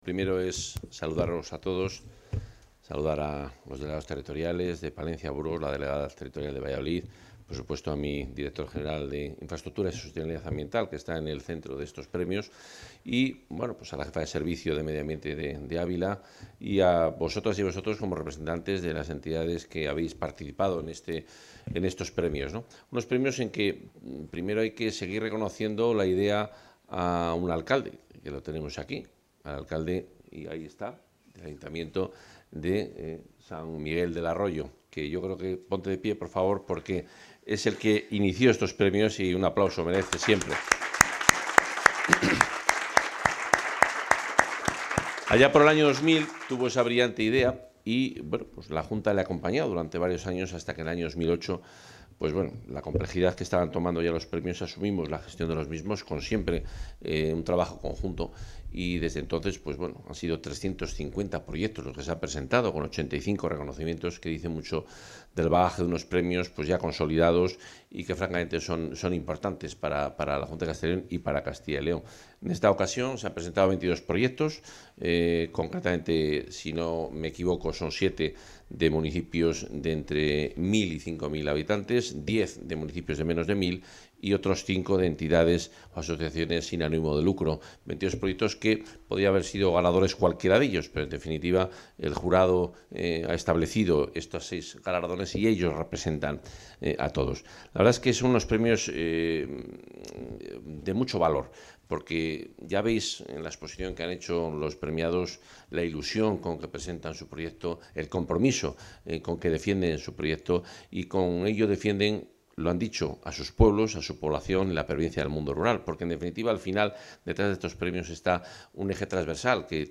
Intervención del consejero.
El consejero de Medio Ambiente, Vivienda y Ordenación del Territorio, Juan Carlos Suárez-Quiñones, ha entregado esta mañana los premios autonómicos 'Fuentes Claras para la sostenibilidad en municipios pequeños de Castilla y León', en su vigésimo primera edición, a seis proyectos desarrollados en distintas localidades de las provincias de Ávila, Burgos, Palencia y Valladolid. Todos ellos han contribuido a la mejora del medio ambiente y de la calidad de vida de sus ciudadanos en el medio rural.